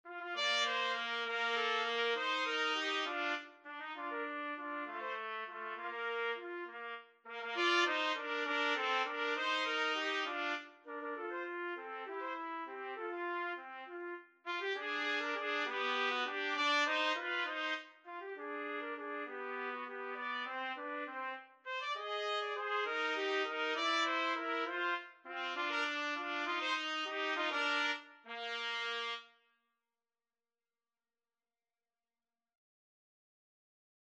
Free Sheet music for Trumpet Duet
Trumpet 1Trumpet 2
3/8 (View more 3/8 Music)
Bb major (Sounding Pitch) C major (Trumpet in Bb) (View more Bb major Music for Trumpet Duet )
Classical (View more Classical Trumpet Duet Music)